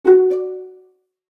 Pizzicato.ogg